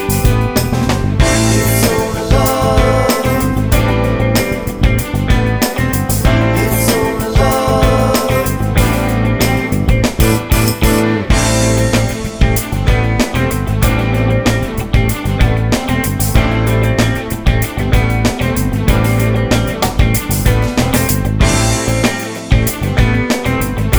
Live Pop (1980s)